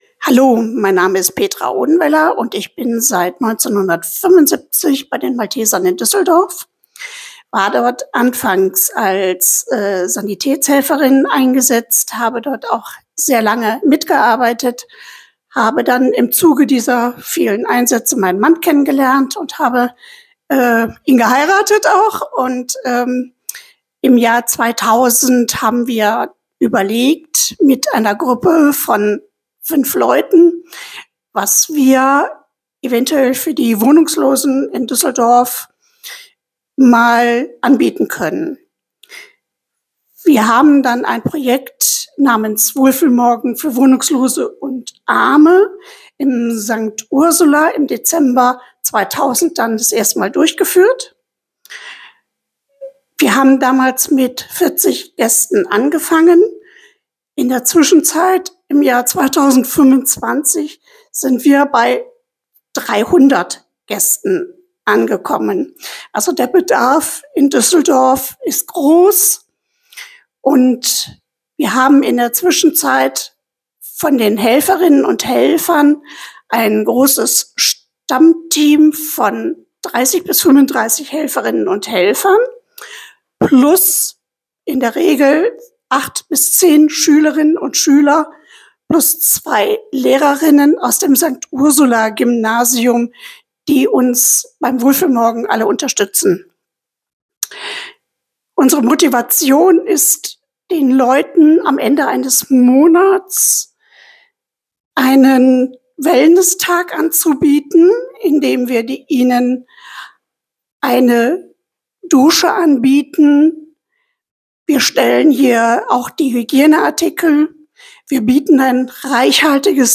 In unserer Audioausstellung erzählen Mitarbeitende und Ehrenamtliche von ihrem Engagement bei den Maltesern in Düsseldorf.